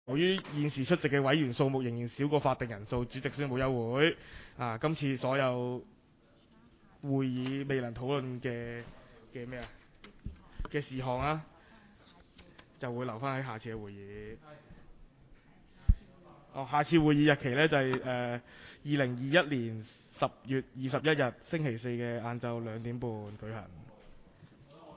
委员会会议的录音记录
地点: 沙田民政事务处441会议室